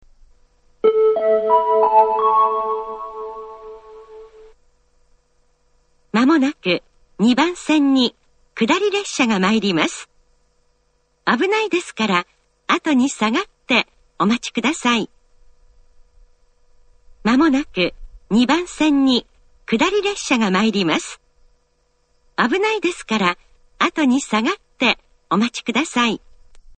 ２番線下り接近放送
tokai-2bannsenn-kudari-sekkinn1.mp3